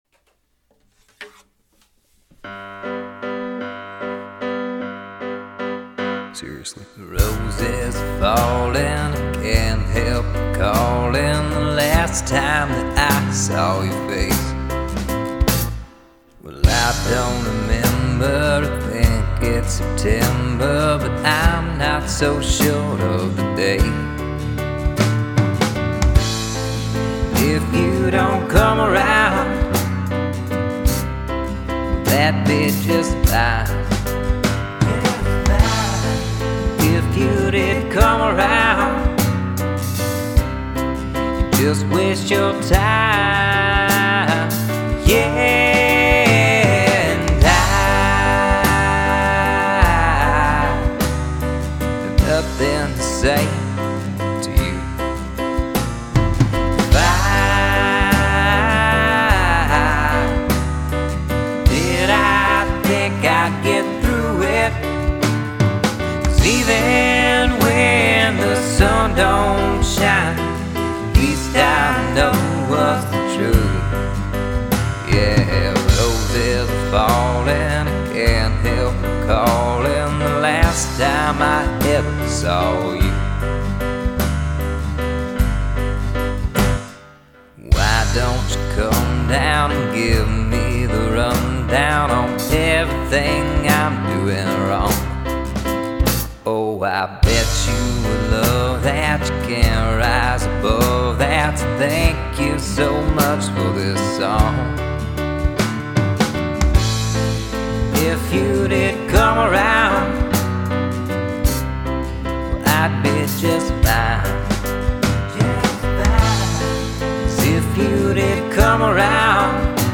Genre: singersongwriter.